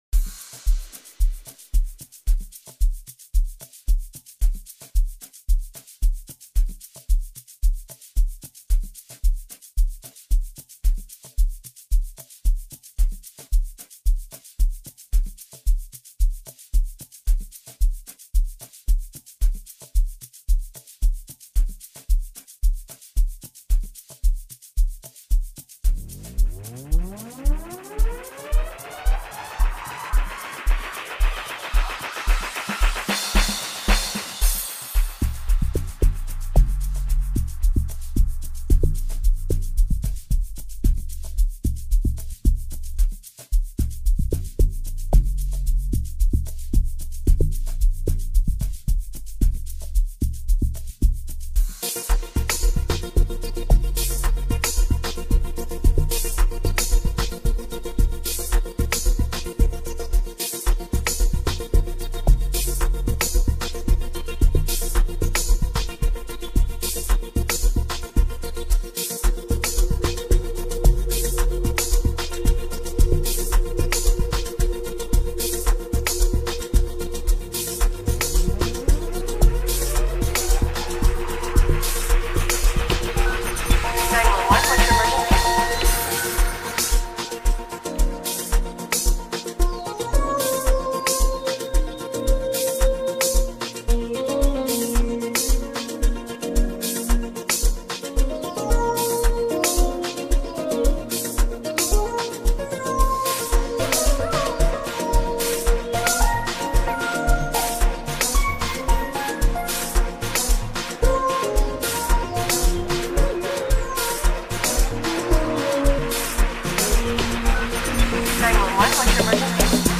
features soothing atmospheres and tunes